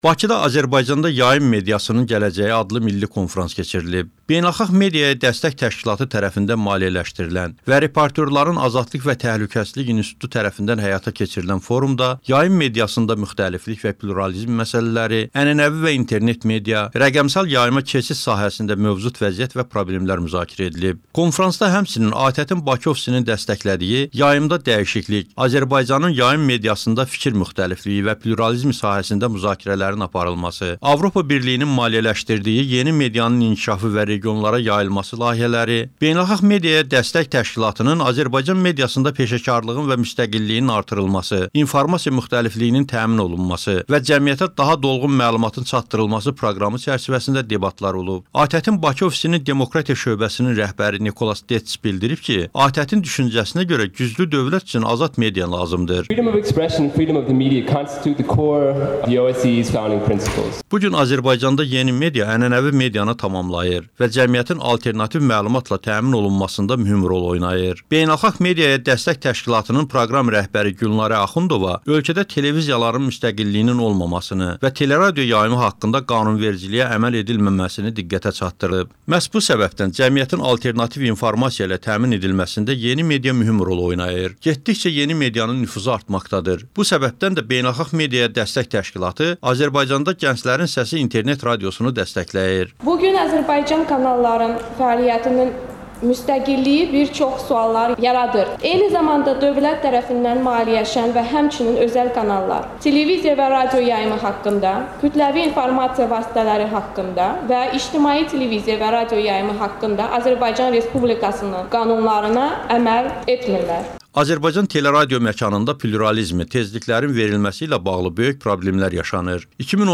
Bakıda “Azərbaycanda Yayım Mediasının Gələcəyi” adlı milli konfrans keçirilib.
"Rəqəmsal yayımda rəqəmli proqramlar" reportajı